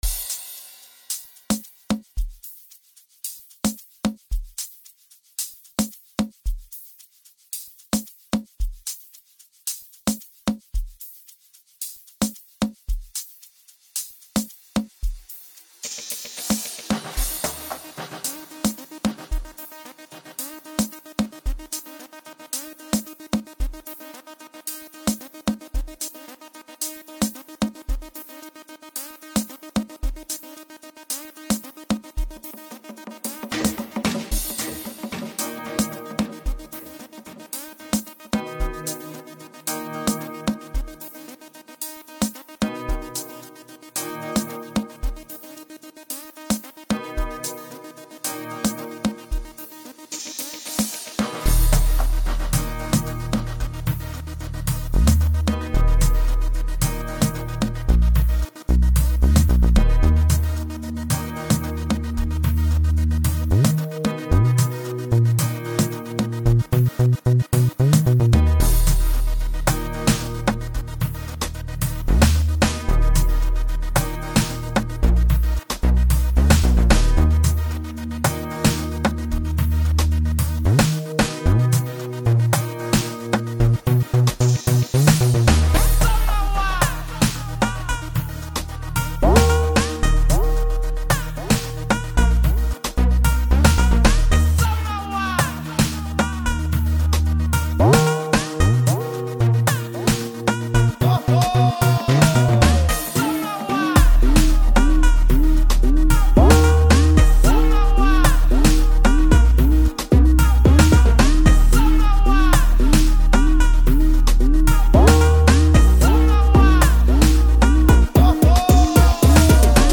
Multi Talented South African Amapiano producer